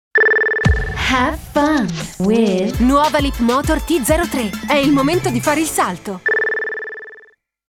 Caldo - Naturale/Semplice